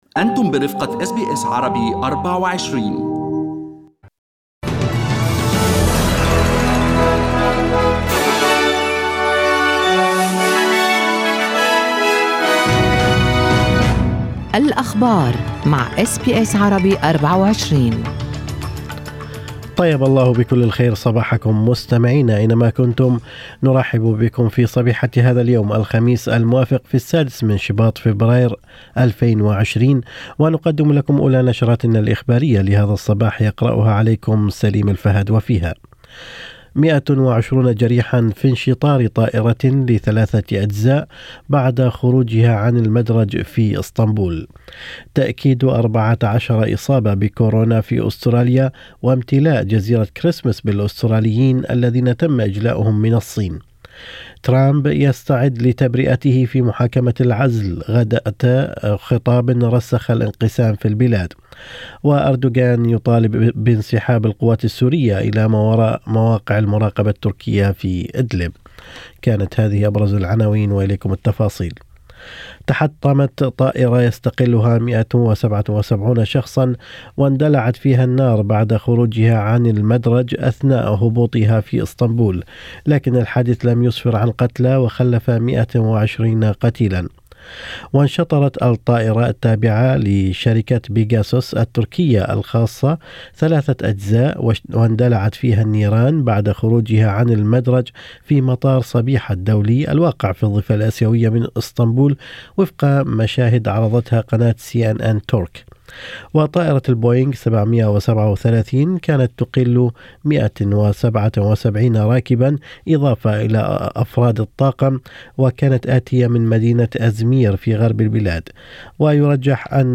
نشرة أخبار الصباح 6/2/2020
Arabic News Bulletin Source: SBS Arabic24